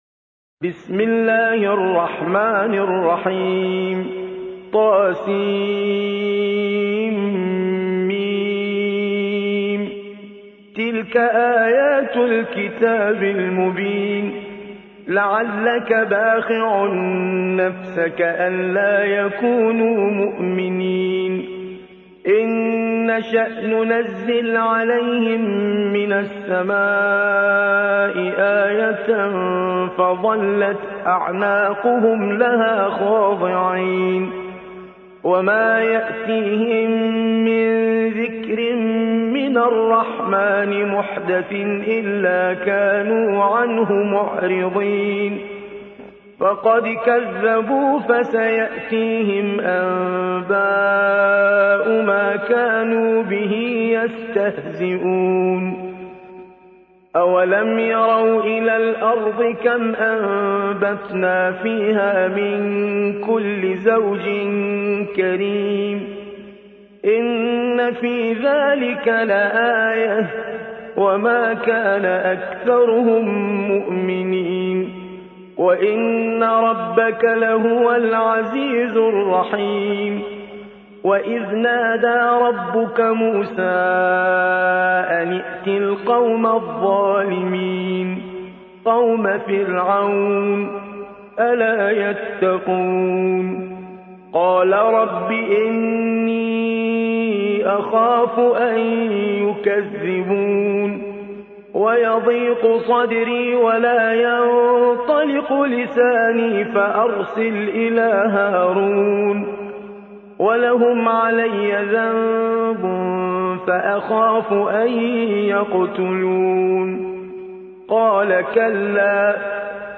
26. سورة الشعراء / القارئ